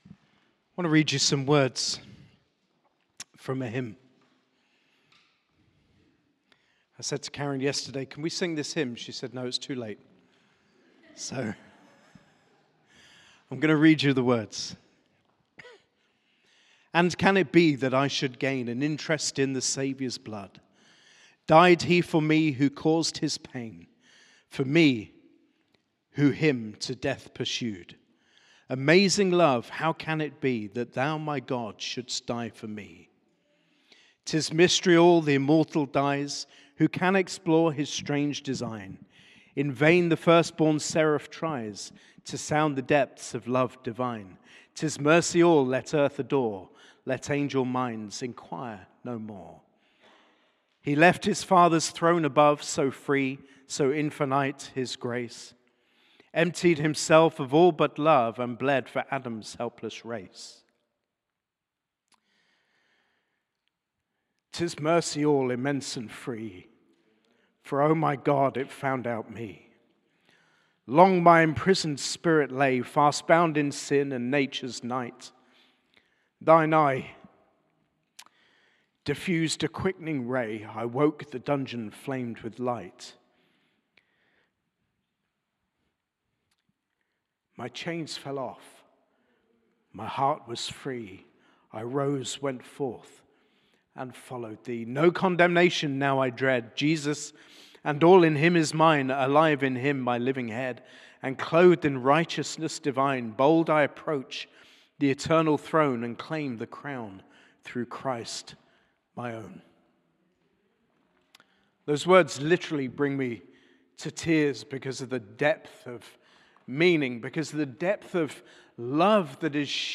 Sermon - Good Friday